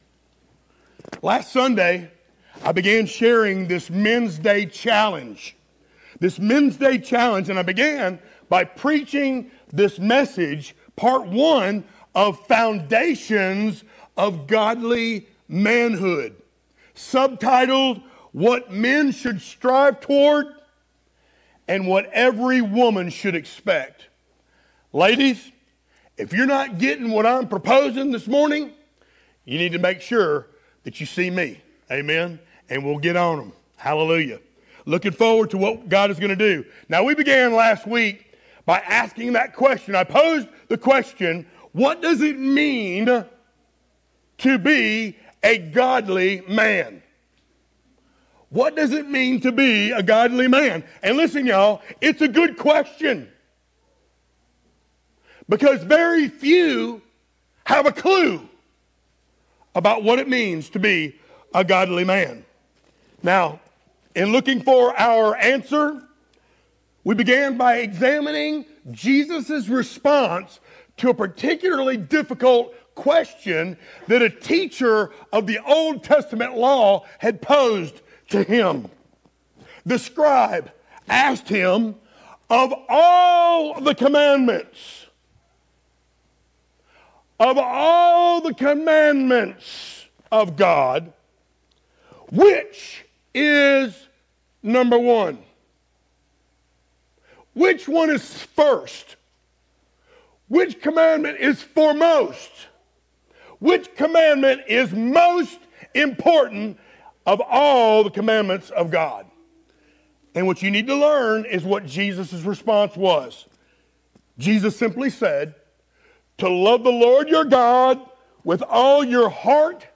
sermons
Mark 12:28-34 Service Type: Sunday Morning Download Files Notes Topics